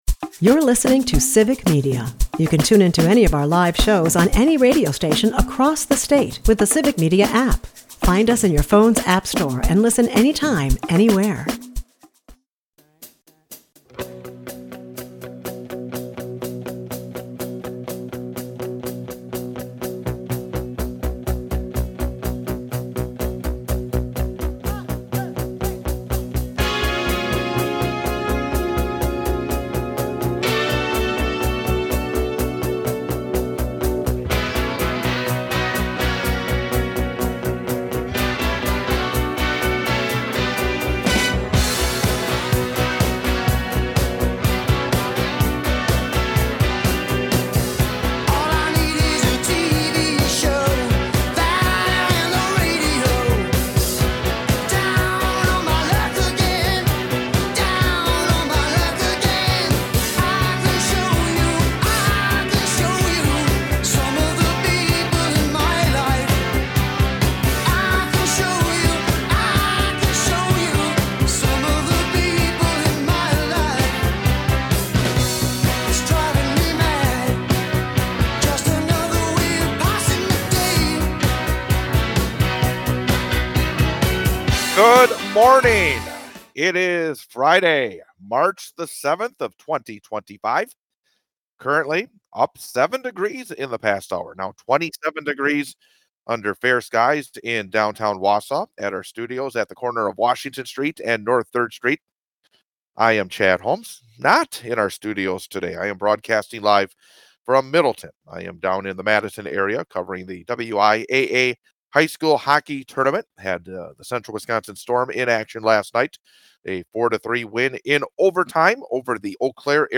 broadcasts from Middleton where he is covering the WIAA state hockey tournament